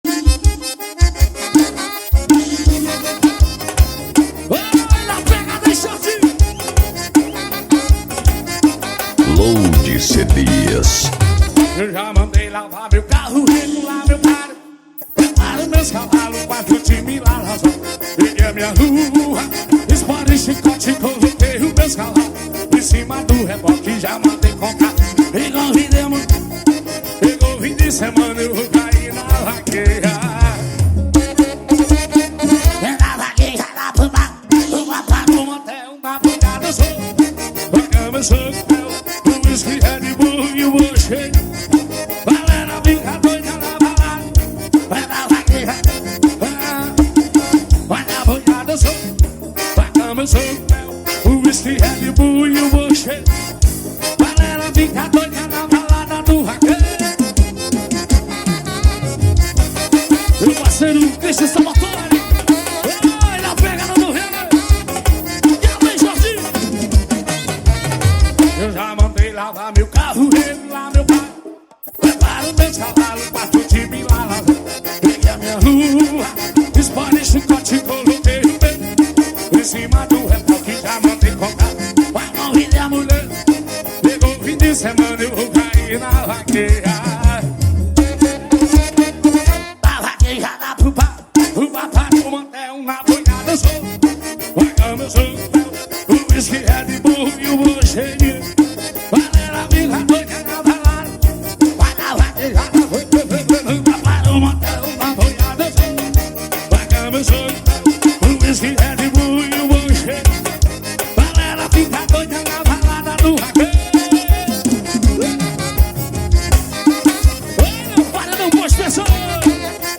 2024-11-04 16:29:58 Gênero: Forró Views